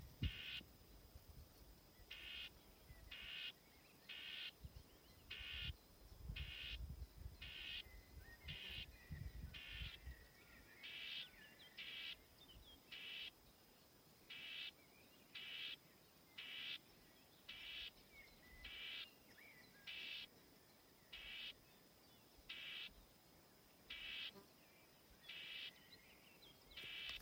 Brūnspārnu ķauķis, Curruca communis
StatussUztraukuma uzvedība vai saucieni (U)
PiezīmesDzirdēts płavā.